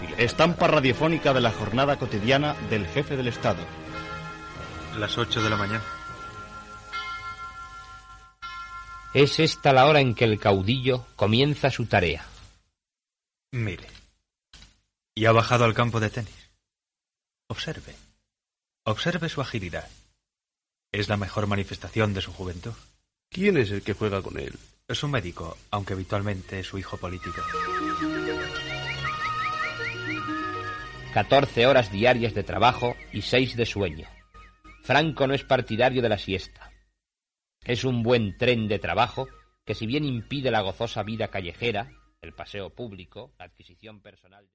"Estampa radiofònica cotidiana del jefe del estado" descripció de com és un jornada de la vida del "generalísimo" Francisco Franco
Divulgació